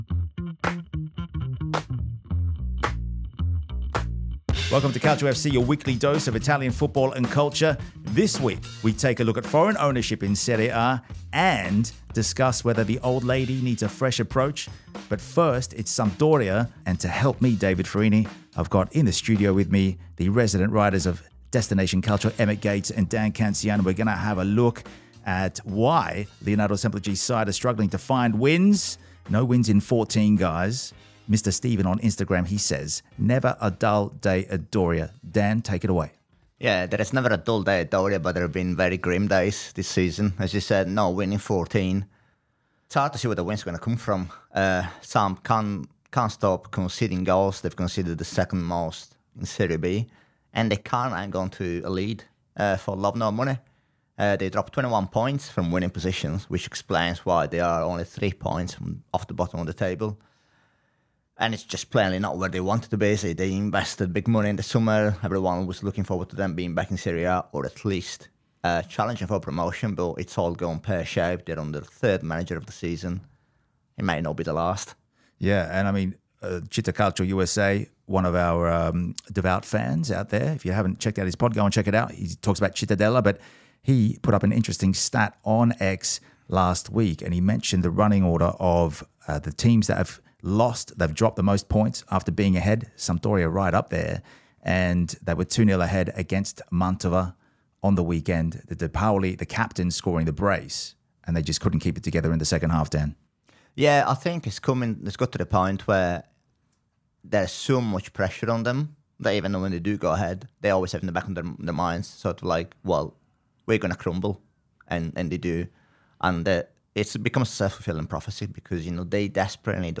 Welcome to Calcio FC, a football podcast by Destination Calcio.